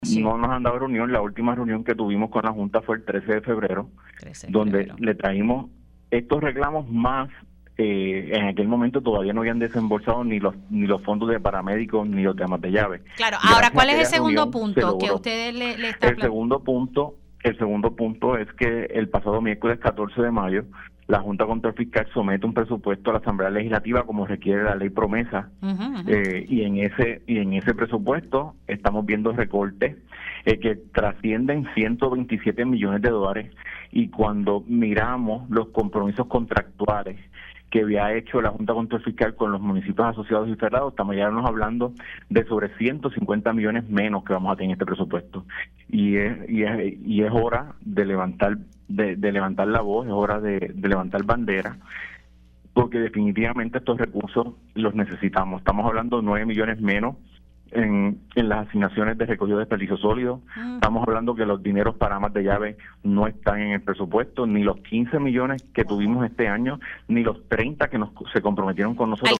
121-GABRIEL-HERNANDEZ-ALC-CAMUY-Y-PRES-FED-ALCALDES-13-DE-FEBRERO-FUE-LA-ULTIMA-REUNION-CON-LA-JSF.mp3